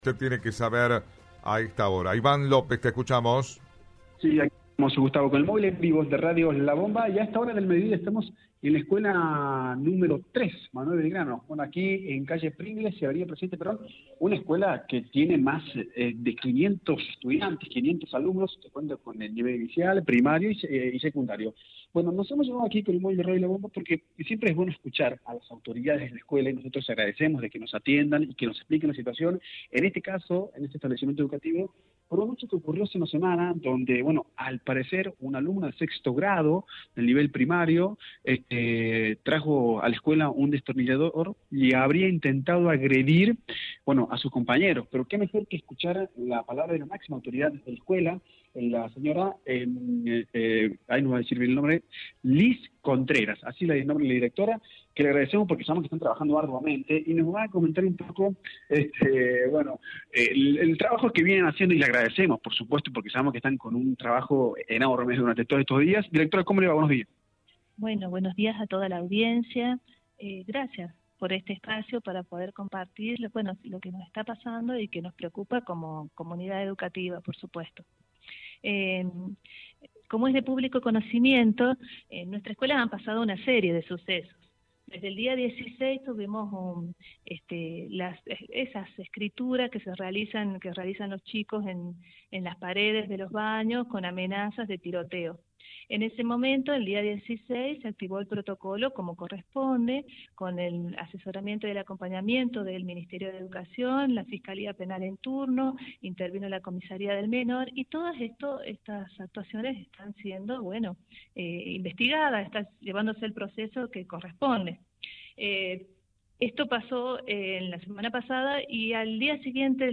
En diálogo con Radio La Bomba